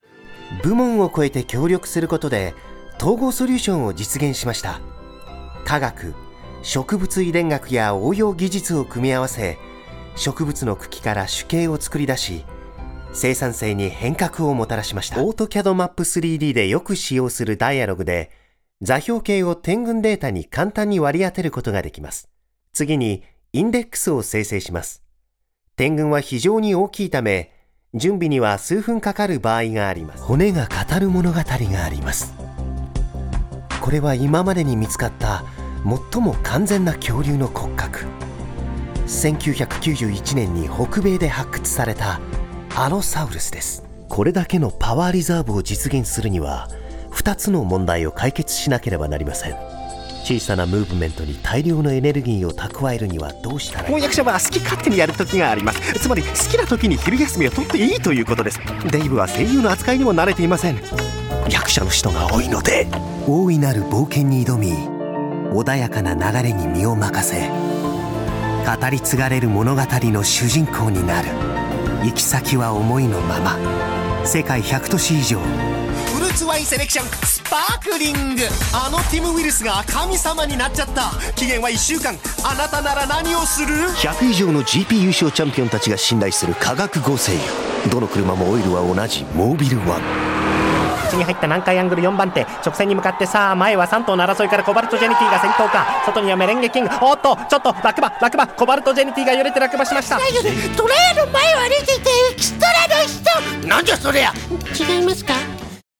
Accomplished VO and actor. Great Ads, promos and characters.